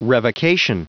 Prononciation du mot revocation en anglais (fichier audio)
Prononciation du mot : revocation